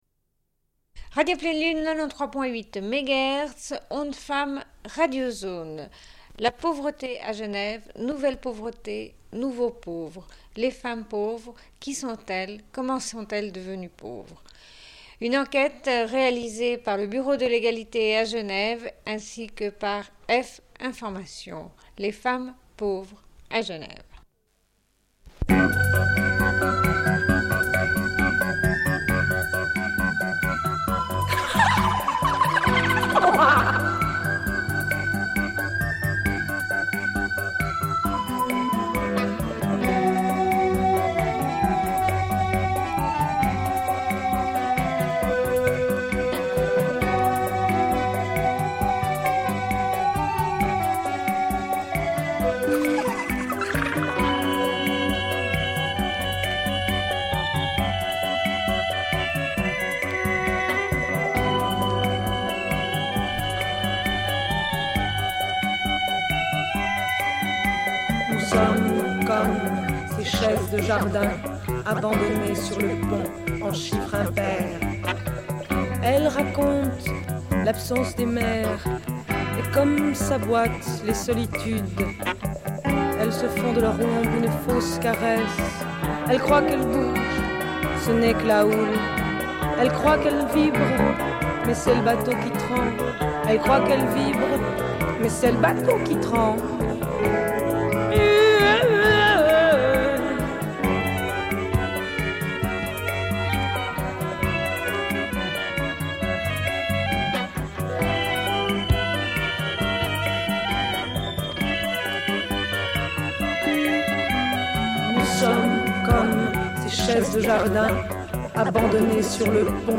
Une cassette audio, face A31:30
Émission sur la pauvreté des femmes à Genève, « une enquête réalisée par le Bureau de l'Égalité à Genève ainsi que par F-Information. Discussion avec des femmes qui ont mené l'enquête. 400 réponses à un questionnaire.